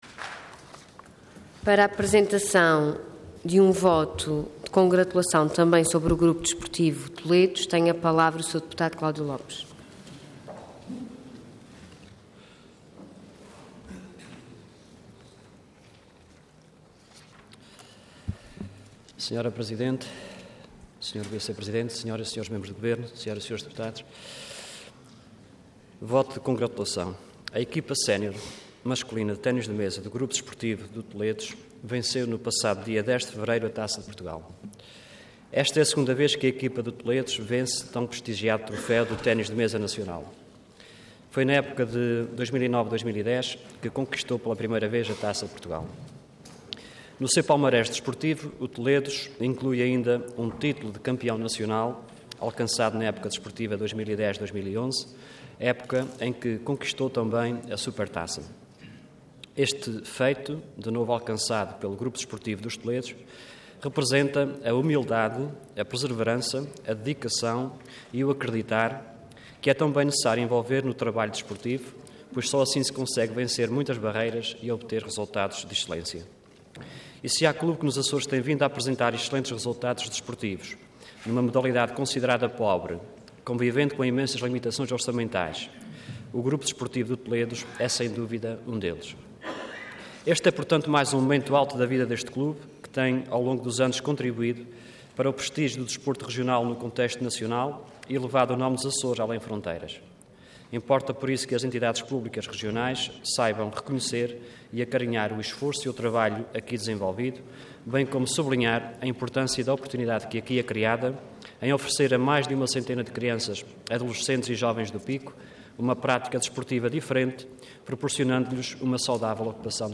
Intervenção Voto de Congratulação Orador Cláudio Lopes Cargo Deputado Entidade PSD